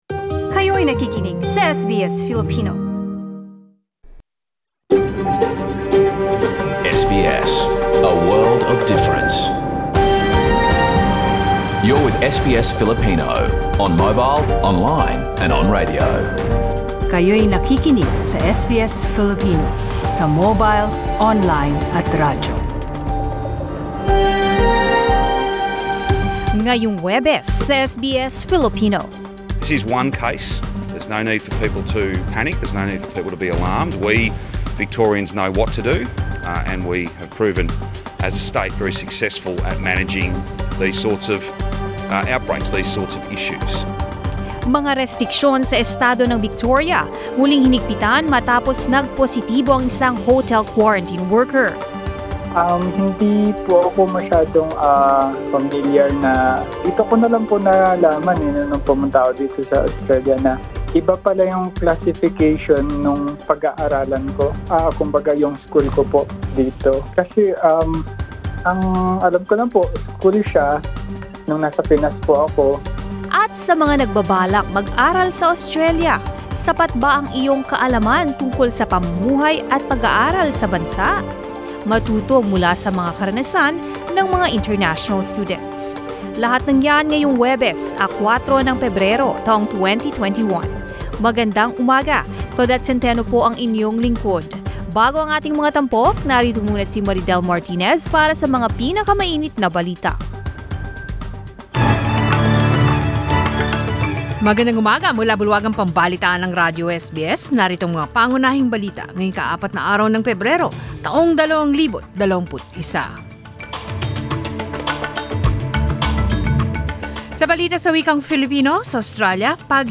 SBS News in Filipino, Thursday 4 February